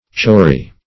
Definition of chowry.
Search Result for " chowry" : The Collaborative International Dictionary of English v.0.48: Chowry \Chow"ry\ (chou"r[y^]), n. [Hind. chaunri.]